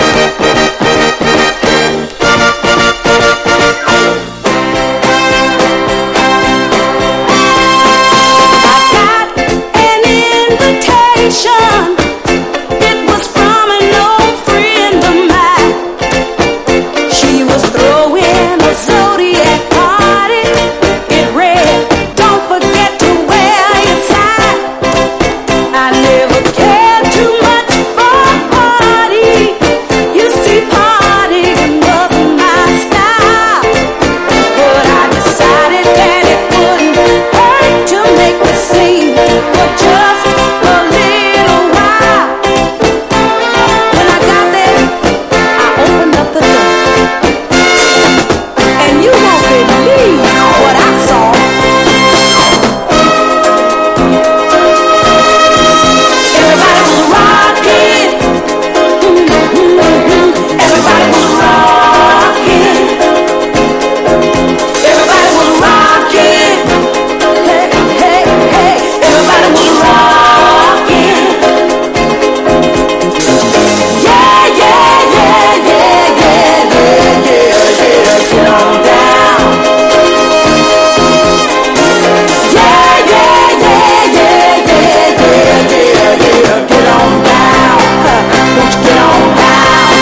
# RARE GROOVE# FUNK / DEEP FUNK# DISCO# サンプリング